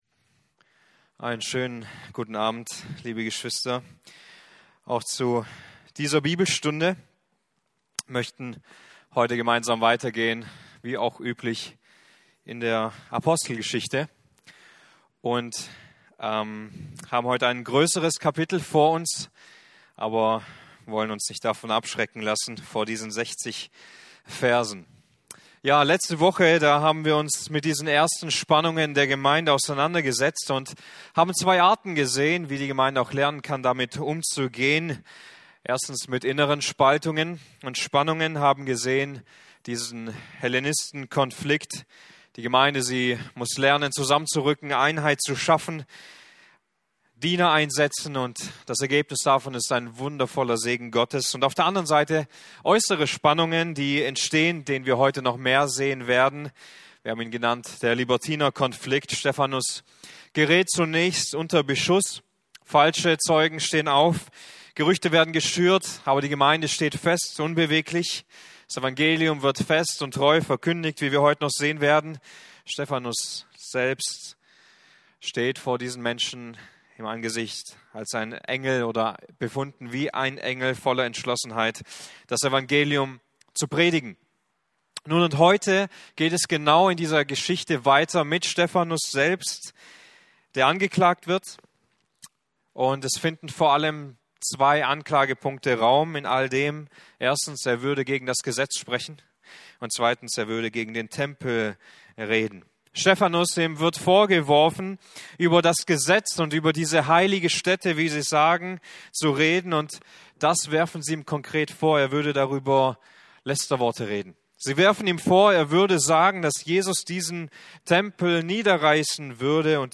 Passage: Apostelgeschichte 4,23-31 Dienstart: Bibelstunden Gottes Plan